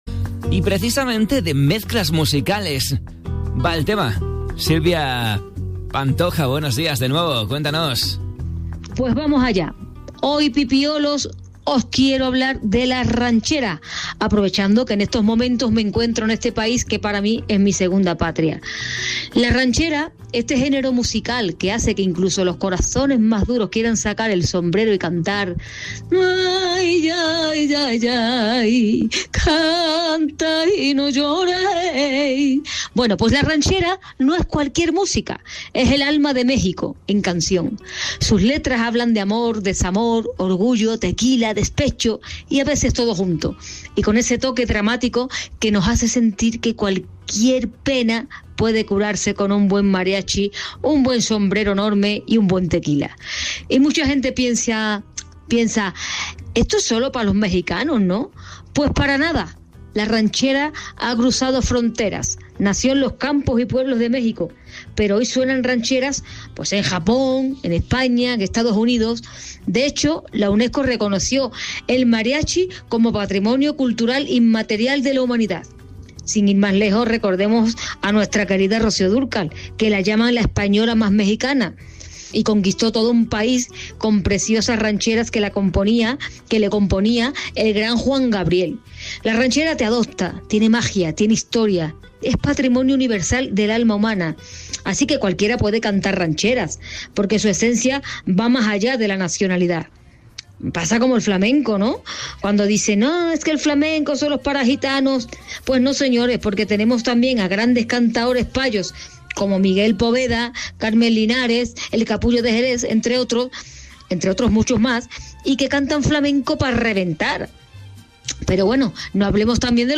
El programa despertador de Radiolé